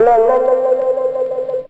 Guitar_Wah.wav